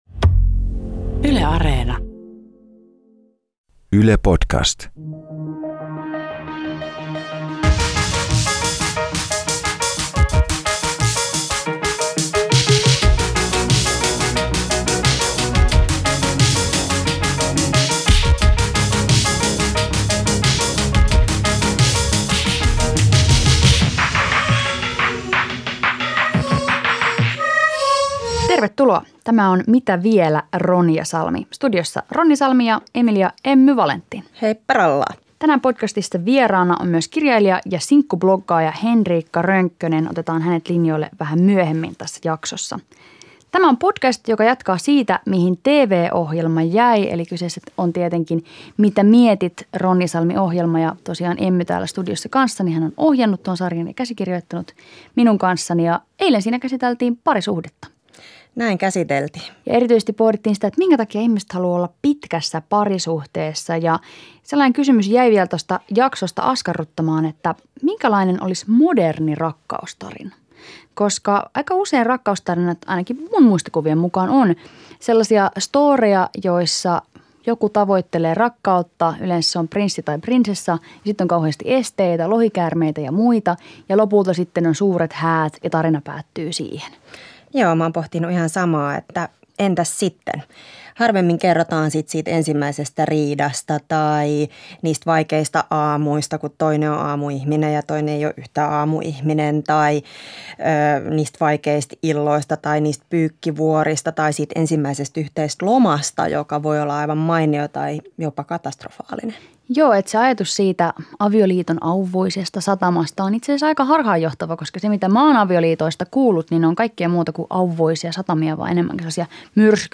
Vieraana on sinkkubloggari ja kirjailija